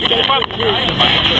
PrivateGB#12 -  Another spirit dropping in to curse somebody.